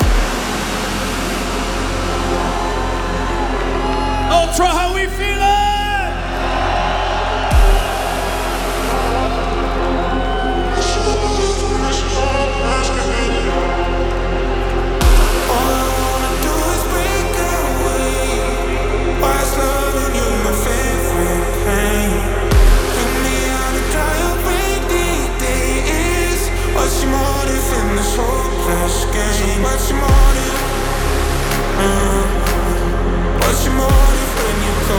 Жанр: Транс